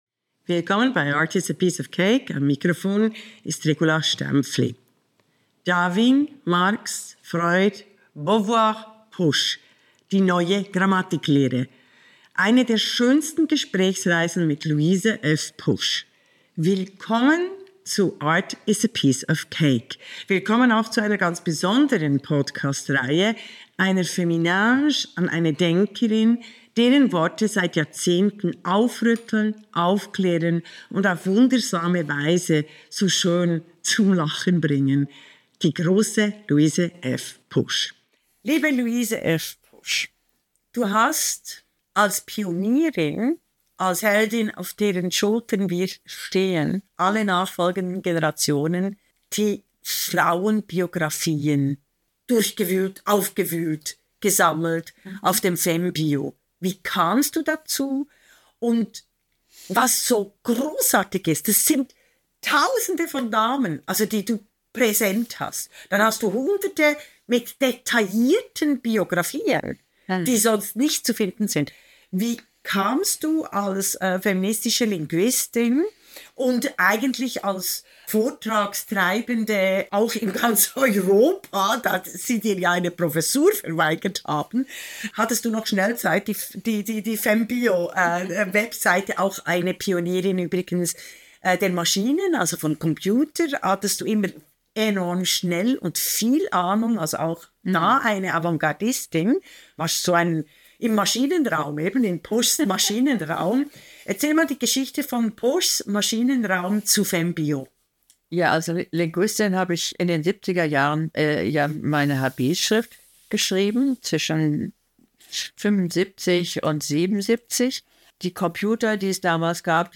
Grammatik als Macht - Poesie als Widerstand. Der dritte Teil der wunderbaren Gespräche